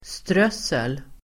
Ladda ner uttalet
Uttal: [²str'ös:el]